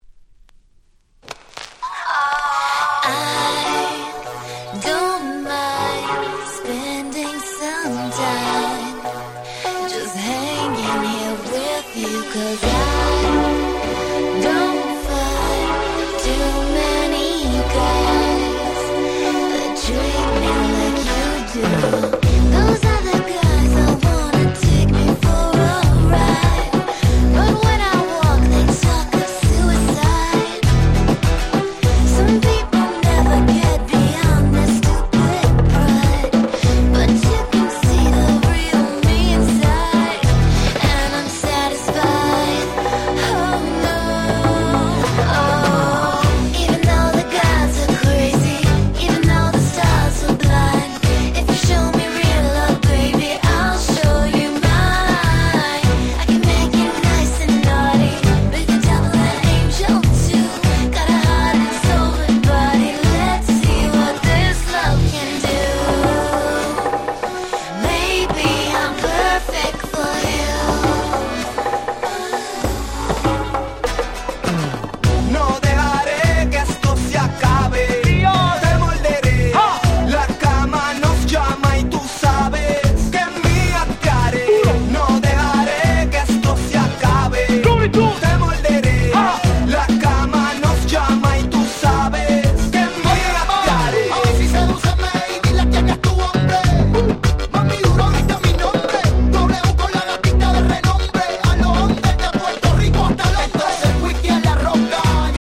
06' Big Hit Pops / R&B !!